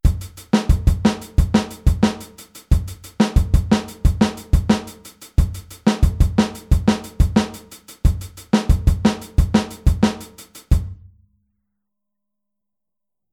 Aufteilung linke und rechte Hand auf HiHat und Snare
Groove24-16tel.mp3